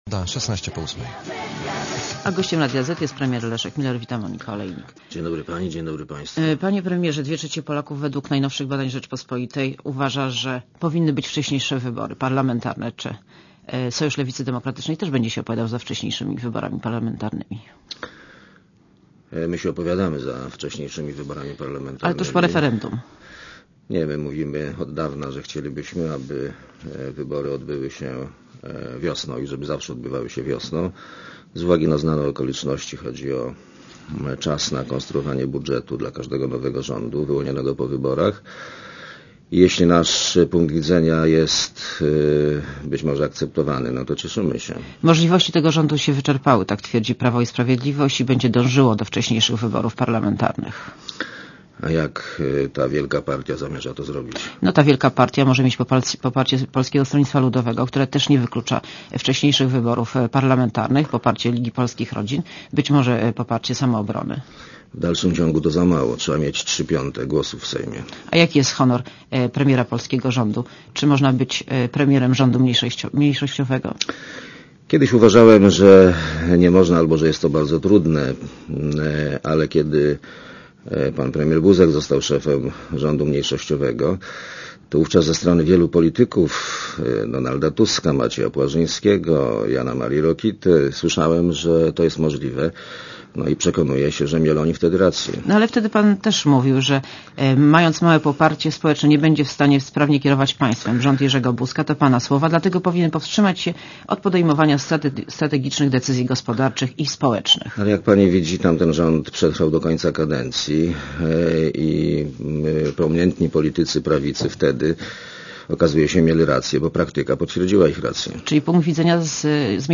Monika Olejnik rozmawia z premierem Leszkiem Millerem Leszek Miller (Archiwum)